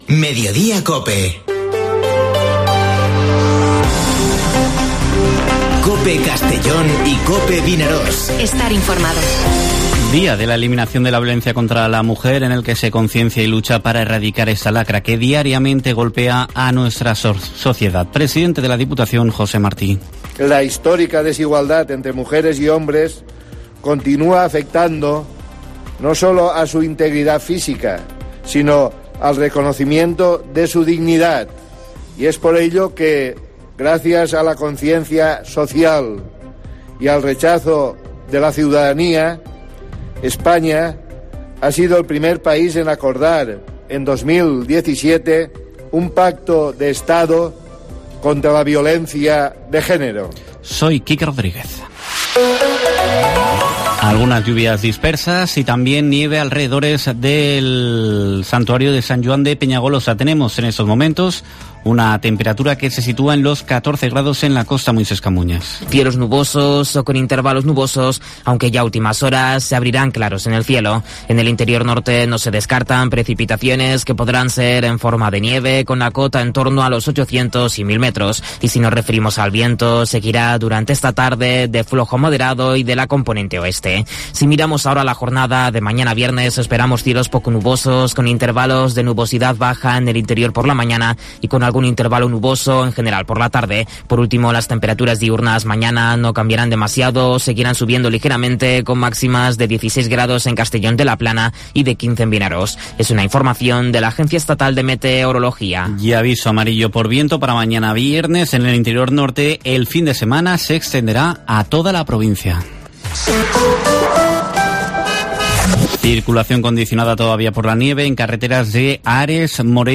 Informativo Mediodía COPE en la provincia de Castellón (25/11/2021)